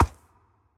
Minecraft Version Minecraft Version 1.21.5 Latest Release | Latest Snapshot 1.21.5 / assets / minecraft / sounds / mob / horse / skeleton / water / soft6.ogg Compare With Compare With Latest Release | Latest Snapshot